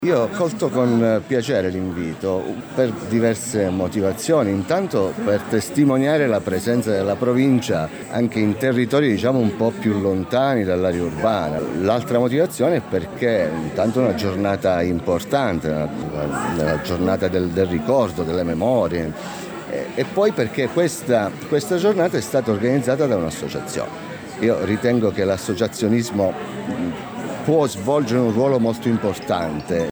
GIORNO DELLA MEMORIA A PRAIA A MARE CON GLI STUDENTI DELL’ALTO TIRRENO
Carlo Lo Prete consigliere provinciale con delega “Politiche Sociali e Rapporti con il volontariato e le associazioni politiche giovanili”.